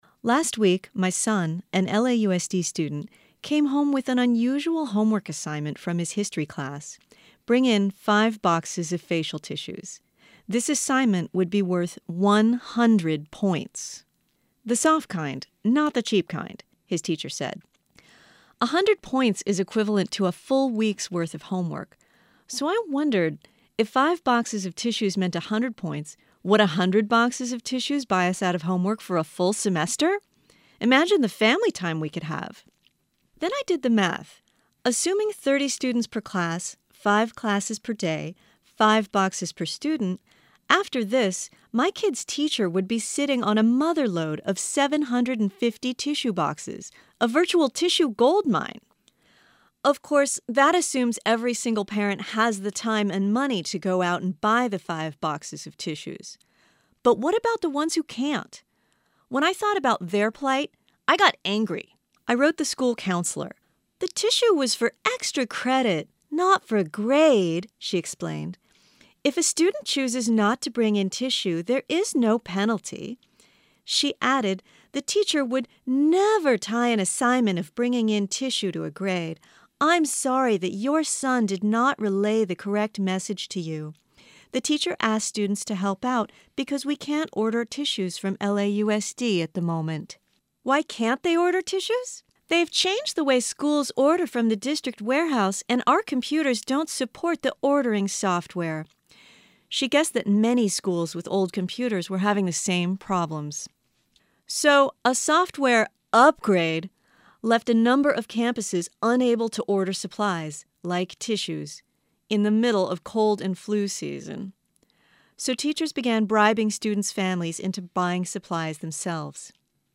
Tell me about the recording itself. Broadcast, KPCC